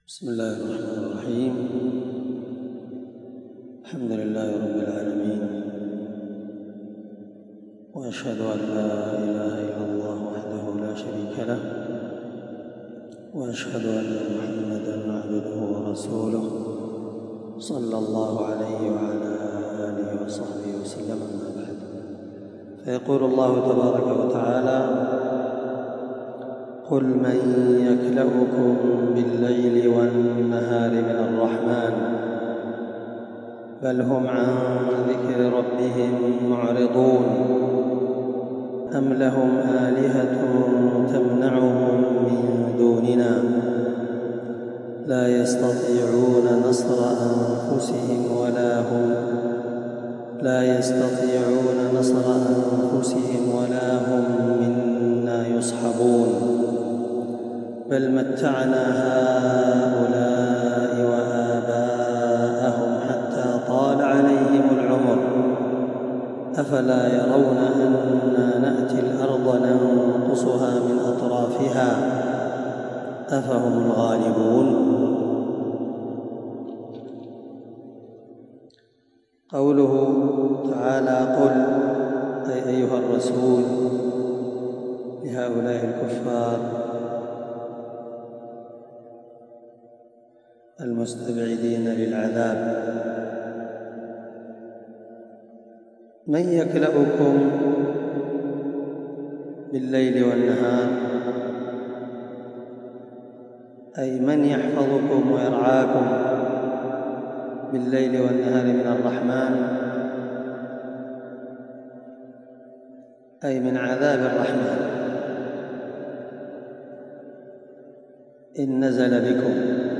21سورة الأنبياء مع قراءة لتفسير السعدي